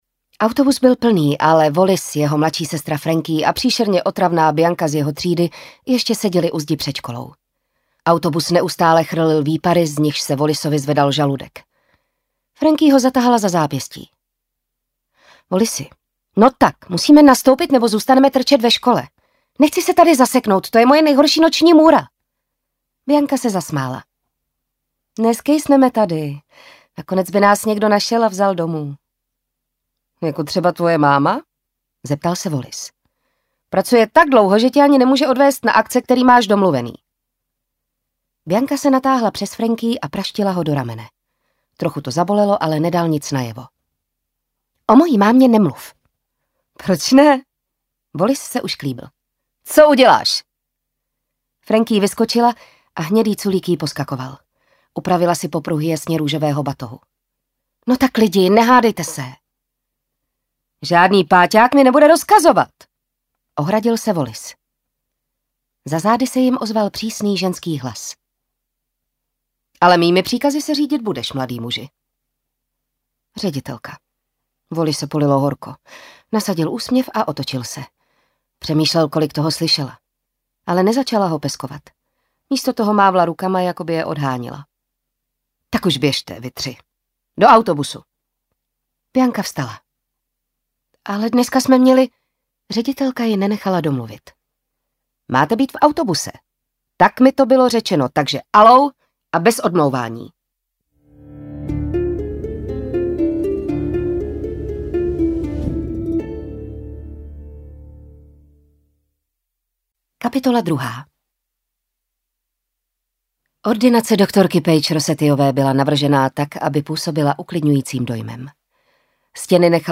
Audiokniha pre dospelých